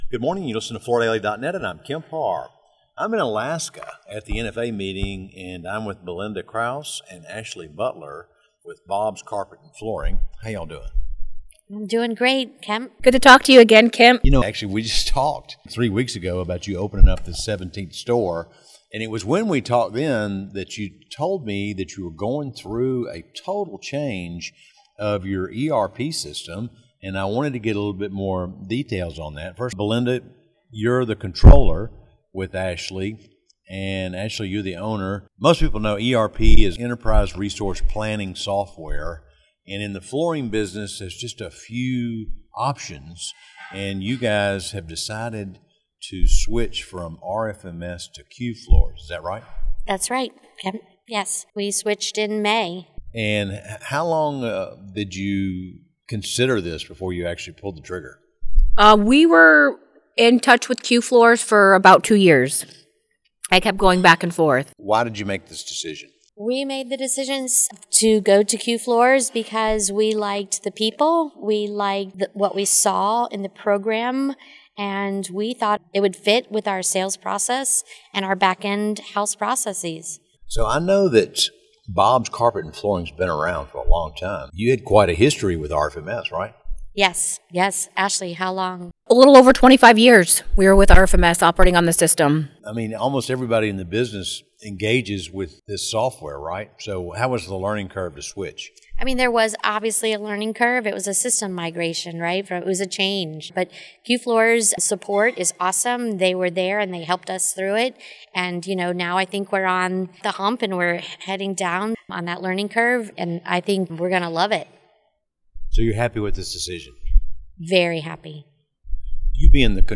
Listen to the interview for details on the commercial market segments, why Interface focuses exclusively on carpet tile, and how the category offers great opportunities for interior design.